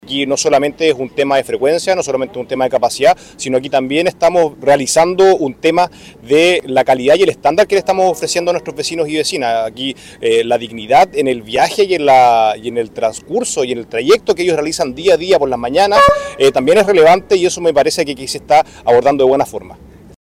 El alcalde de San Pedro de la Paz, Juan Pablo Spoerer, indicó que estamos muy contentos, principalmente por la seriedad con la que EFE y el gobierno ha ido trabajando todo el itinerario que se conversó desde que nosotros asumimos como administración, tanto en la puesta en marcha del puente ferroviario, también con la incorporación de nuevos trenes, y lo que también esperamos que siga cumpliéndose de buena forma en octubre”.